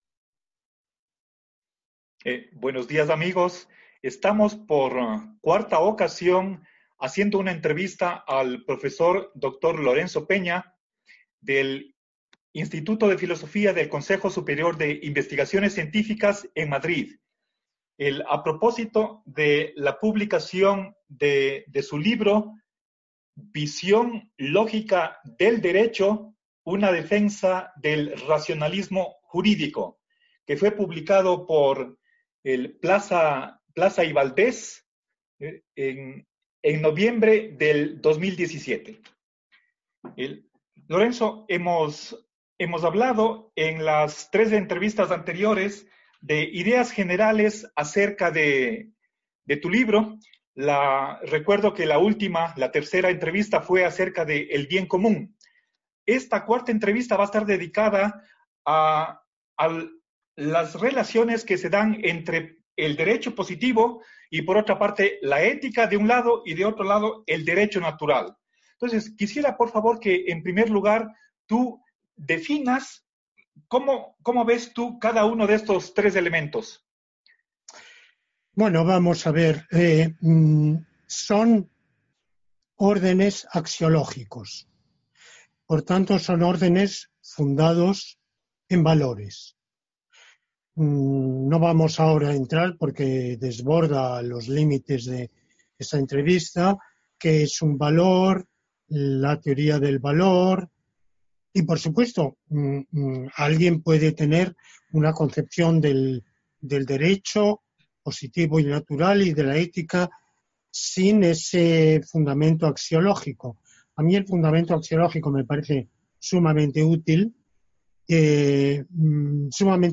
Entrevista_sobre_VLD4.mp3